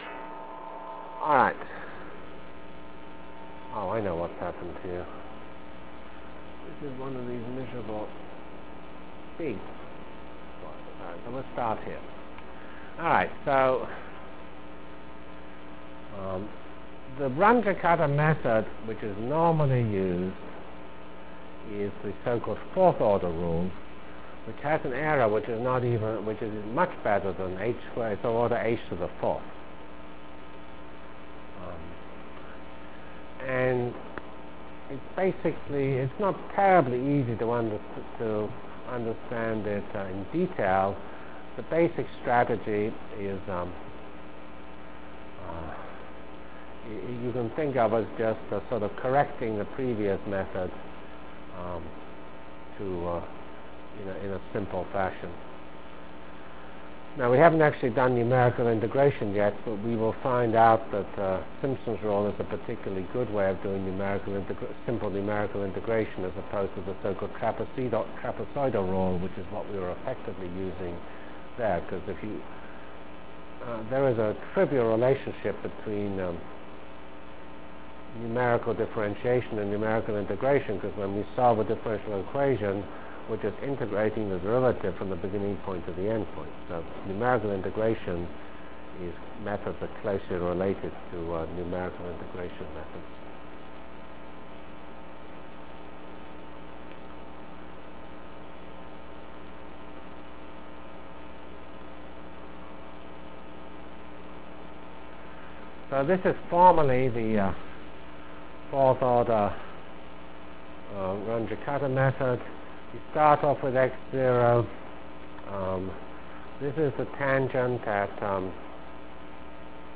From CPS615-Discussion of Ordinary Differential Equations and Start of Parallel N-Body Algorithm Delivered Lectures of CPS615 Basic Simulation Track for Computational Science -- 10 October 96. by Geoffrey C. Fox